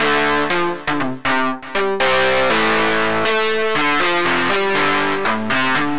synth guitar
Aj na subtractore sa da nagenerovat umela gitara pri trochu snazeni sa, hoc to znie furt velmi umelo a velmi nie verne...ale nic lepsie synteticke som nepocul :-)
synth_guitar_dist_05.mp3